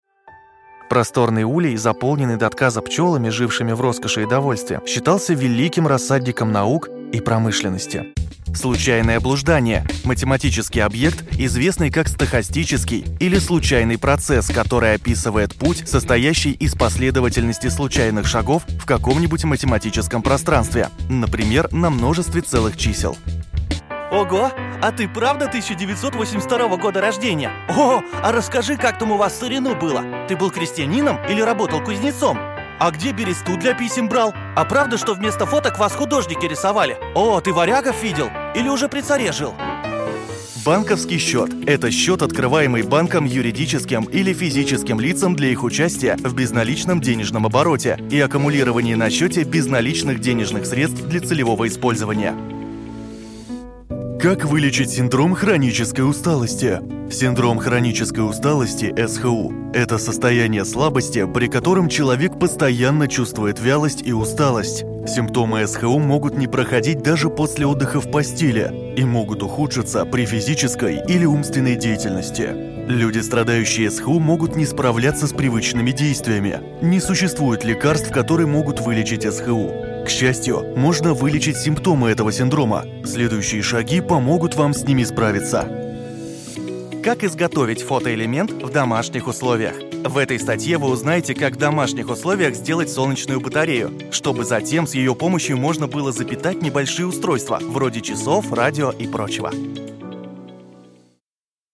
Микрофон: Neumann u87ai, Audio-Technica AT4040
Следующий диктор >> В банк голосов Отзывы клиентов Диктор, звукорежиссер.
Мой молодой и бодрый голос придаст красок и настроения вашим проектам.